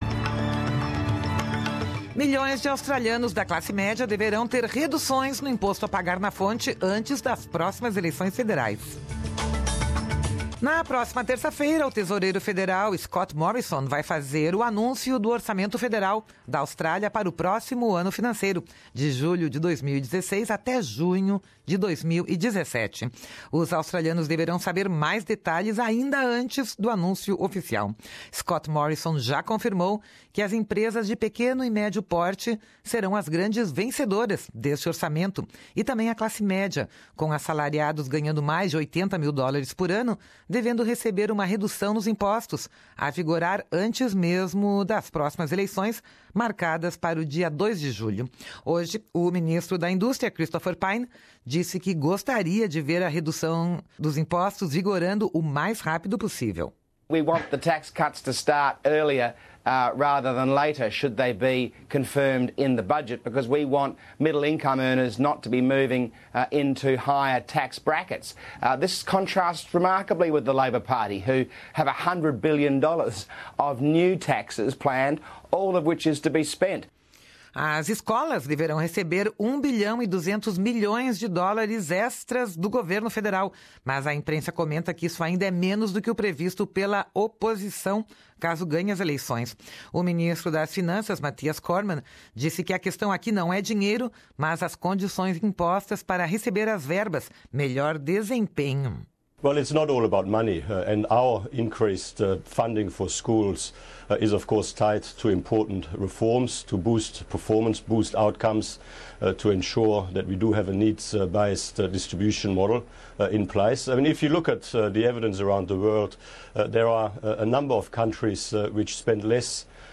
A redução do imposto retido na fonte poderá entrar em vigor ainda antes das próximas eleições, marcadas para o dia 2 de julho. Aqui a reportagem da Rádio SBS.